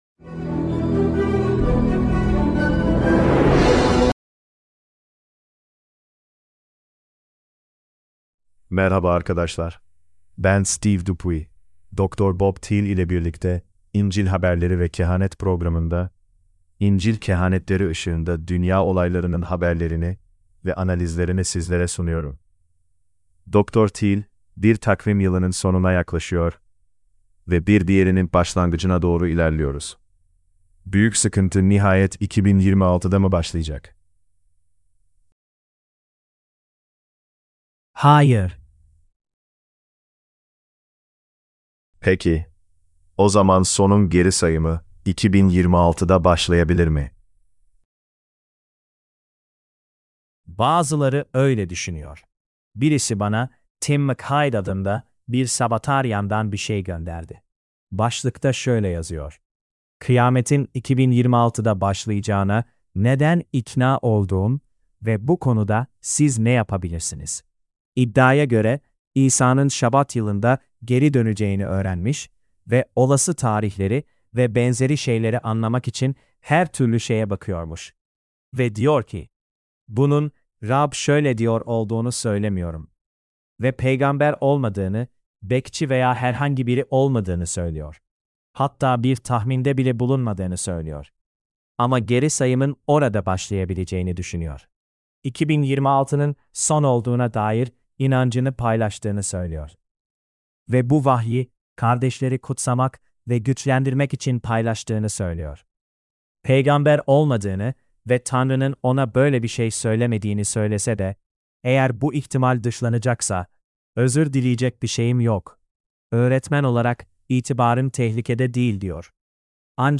Turkish Sermonette – Bible Prophecy News